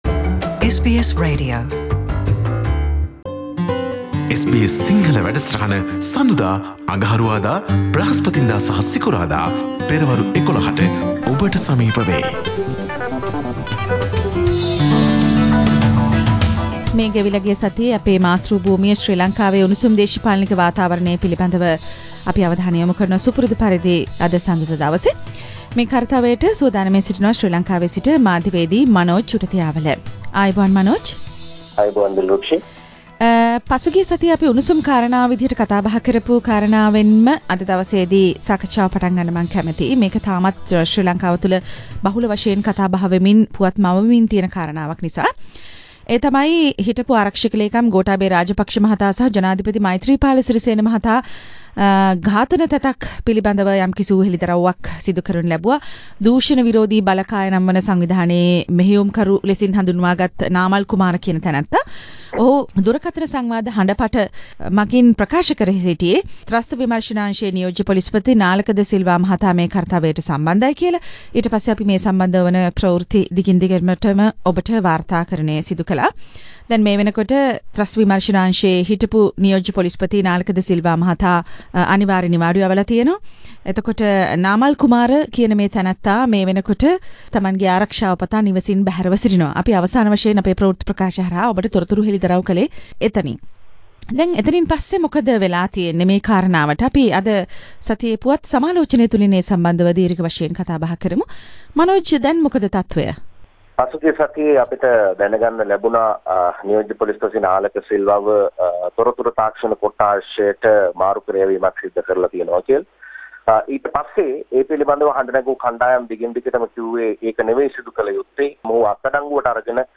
පුවත් සමාලෝචනය.